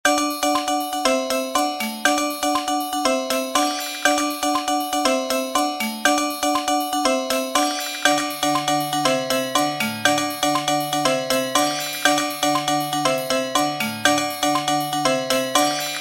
zuk-bell-bell_24598.mp3